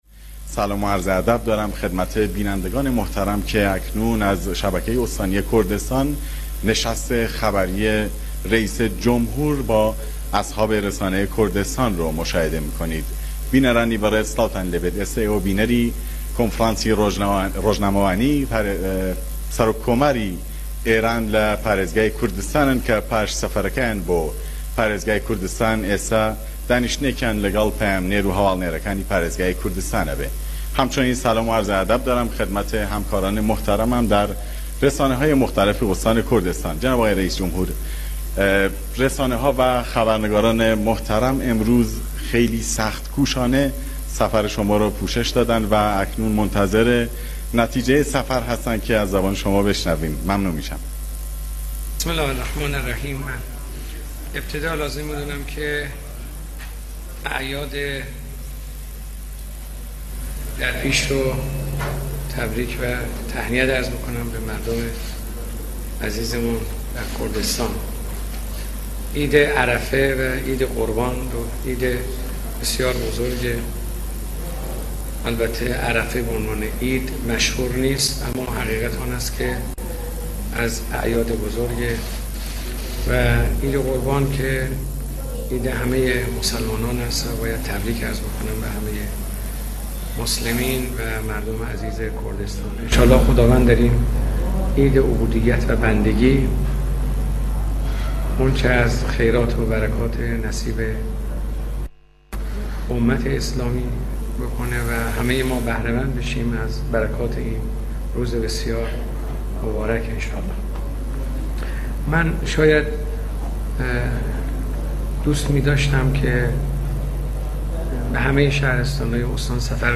آیت الله رئیسی در پایان سفر استانی به کردستان در نشست خبری حضور یافت و به سوالات خبرنگاران پاسخ داد.